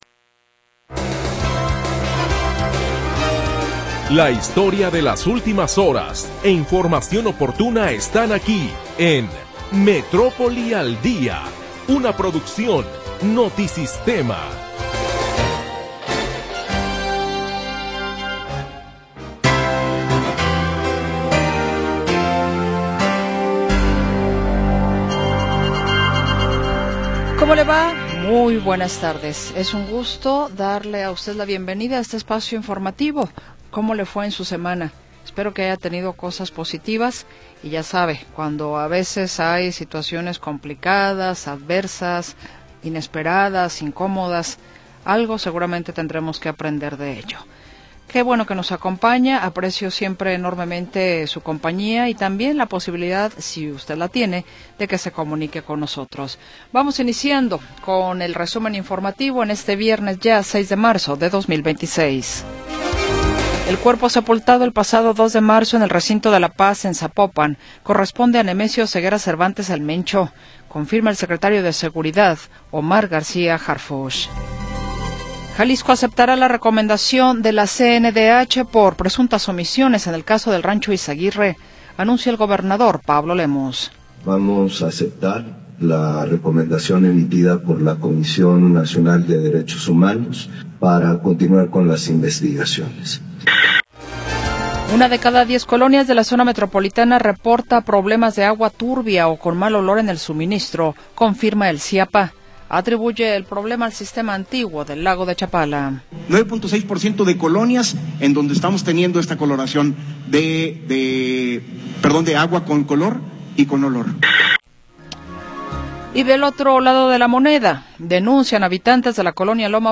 Primera hora del programa transmitido el 6 de Marzo de 2026.